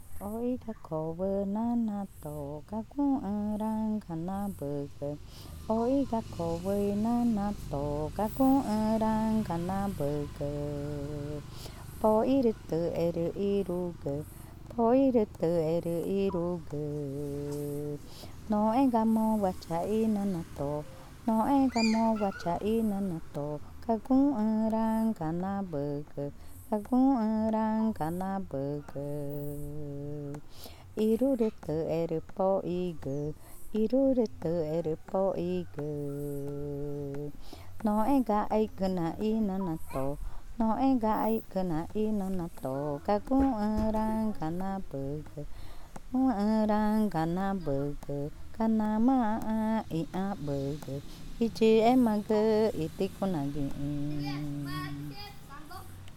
Cushillococha
Canción infantil sobre Iya iya